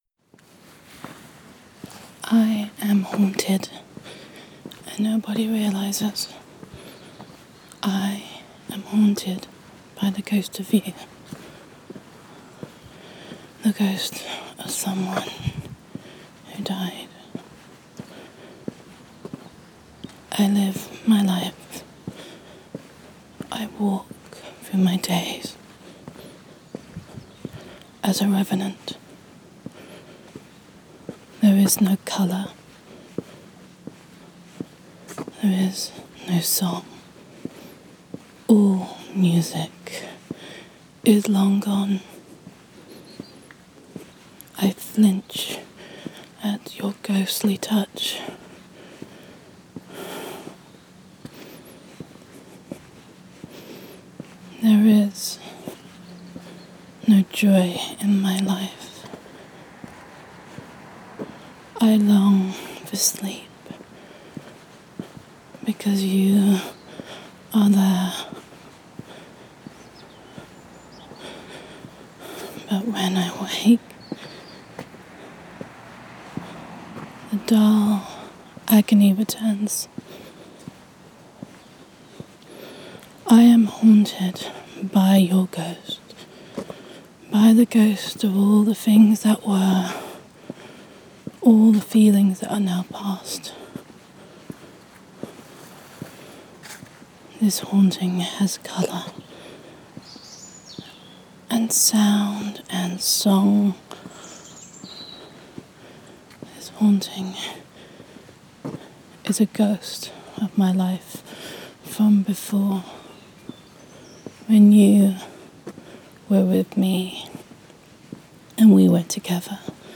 [F4A]
[Melancholy][Walking with Your Ghost]